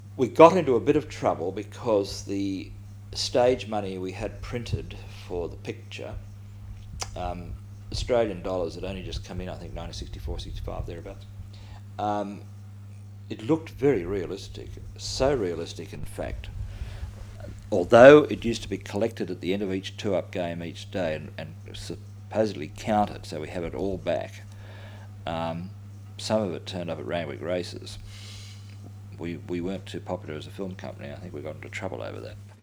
This short excerpt is from an Oral History interview